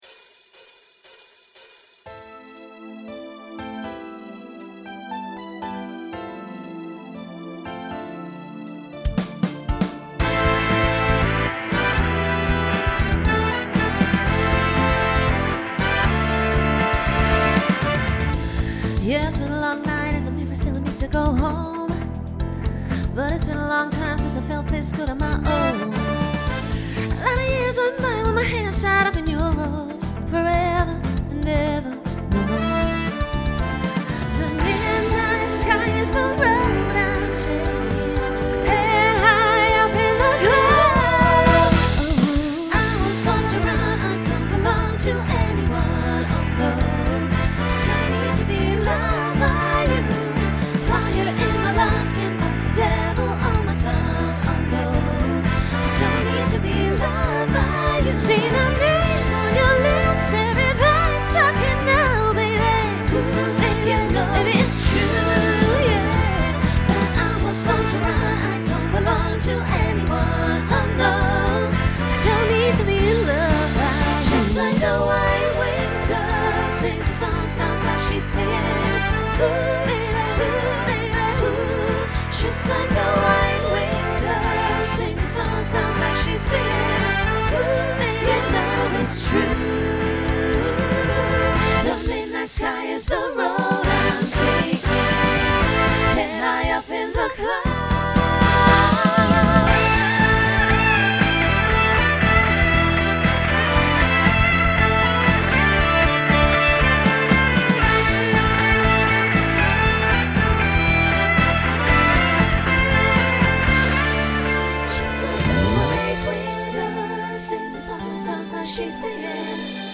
Genre Pop/Dance , Rock
Opener Voicing SSA